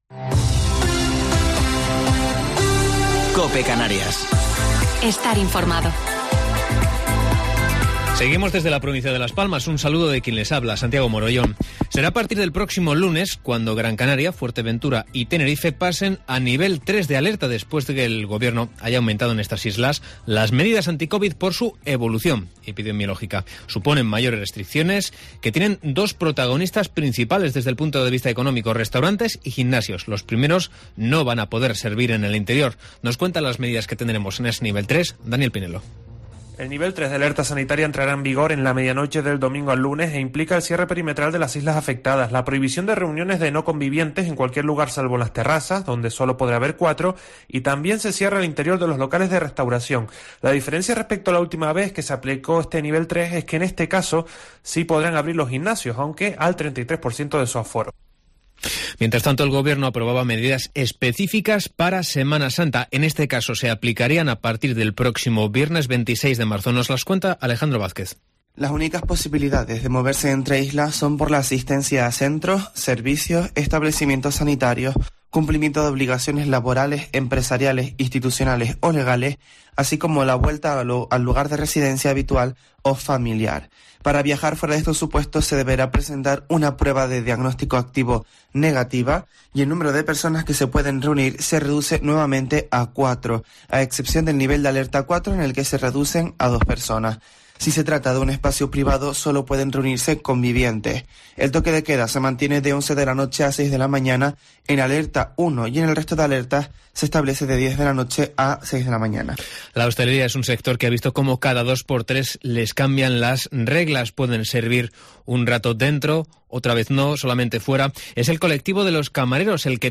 Informativo local 19 de Marzo del 2021